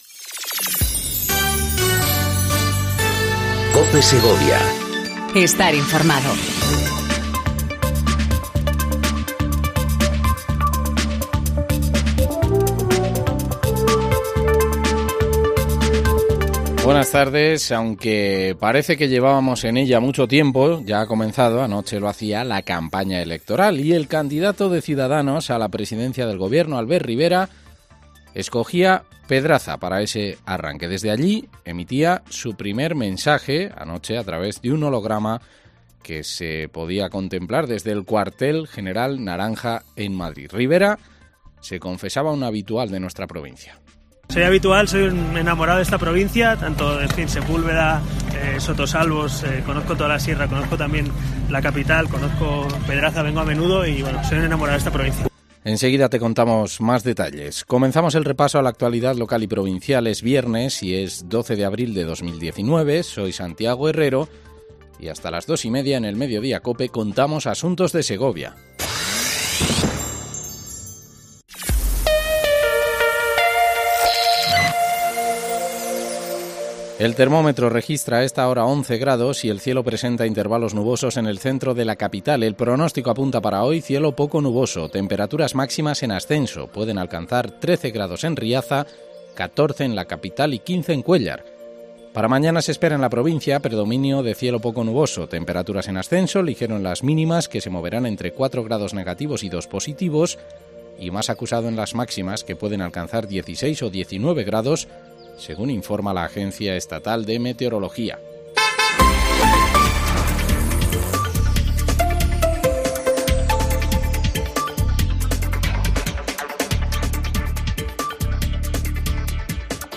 INFORMATIVO DEL MEDIODÍA EN COPE SEGOVIA 14:20 DEL 12/04/19